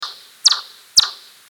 Lepakot ääntelevät lentäessään, mutta niiden ääni on niin korkeataajuista, että sitä ei yleensä ihmiskorvin kuule.
Koska me emme ääniä kuule, lepakoita havainnoidaan nykyisin tavallisesti nk. lepakkodetektorilla, joka on yliääniä kuultaviksi muuntava laite.
viiksisiippalaji_janakkala_2009.mp3